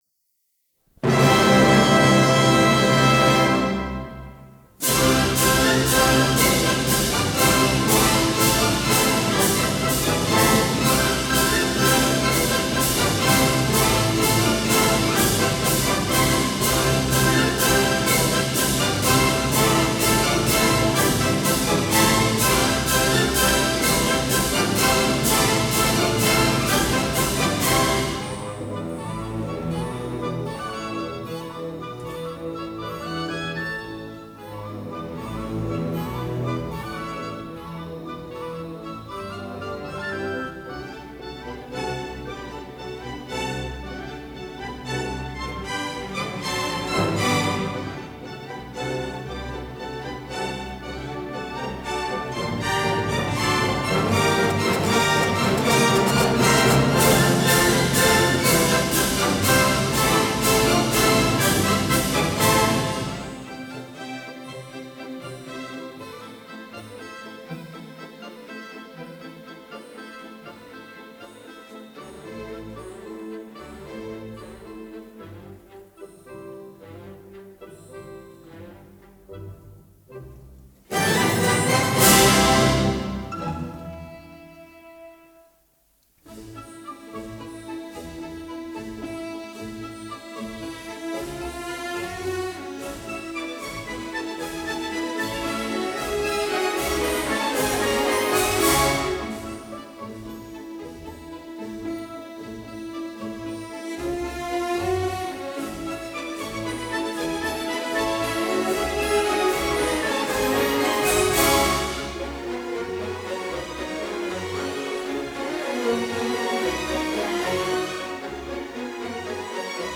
Recorded June 1960 at Sofiensaal, Vienna